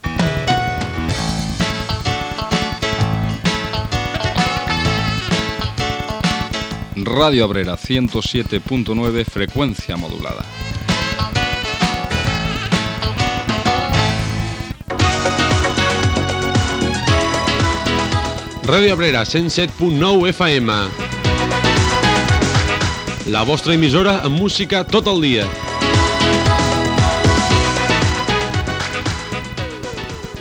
Indicatius de l'emissora